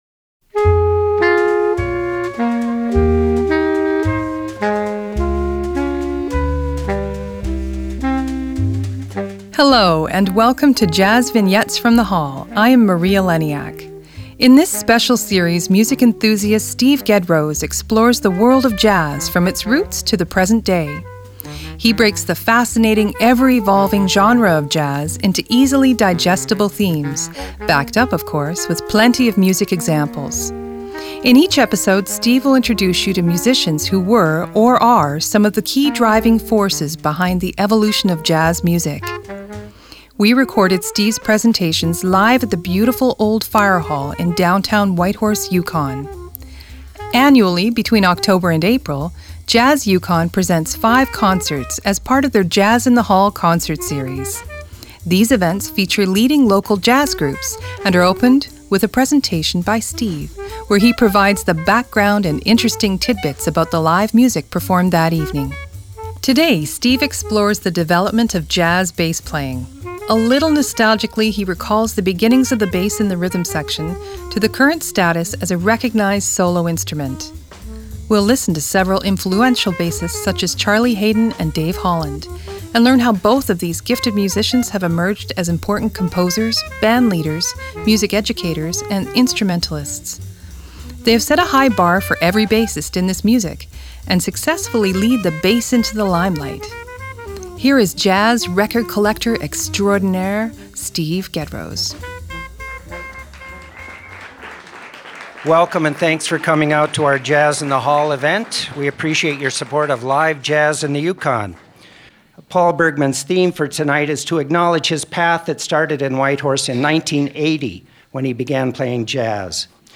JVFTH_39_-_Jazz_Bass.mp3 72,246k 320kbps Stereo Comments
JVFTH_39_-_Jazz_Bass.mp3